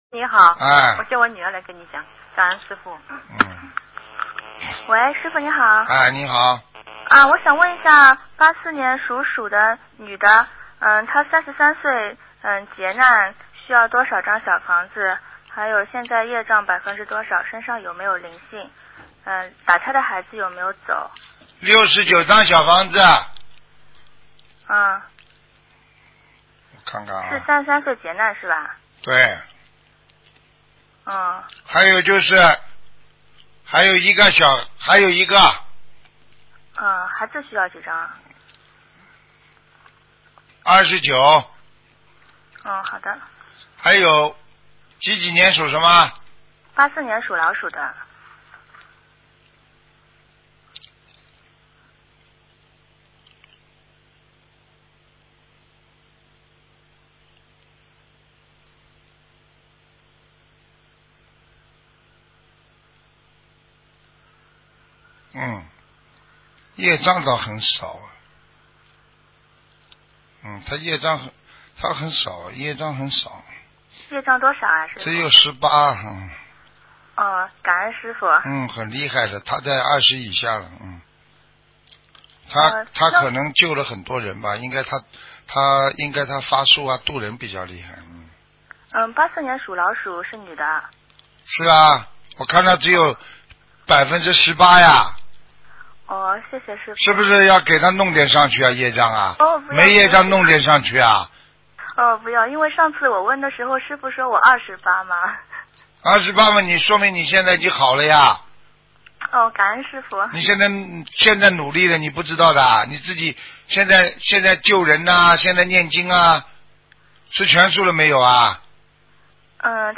目录：2016年剪辑电台节目录音_集锦